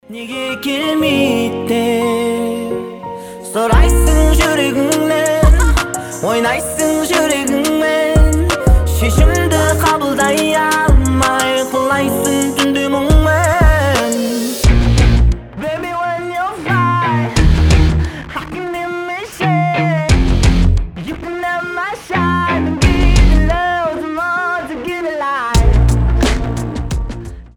• Качество: 320, Stereo
красивый мужской голос